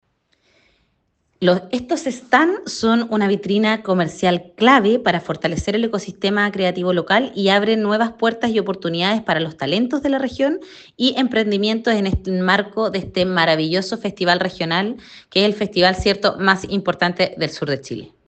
La seremi de las Culturas, Paloma Zúñiga, enfatizó que “en esta décima edición del REC buscamos fortalecer el vínculo entre la cultura y el desarrollo regional”.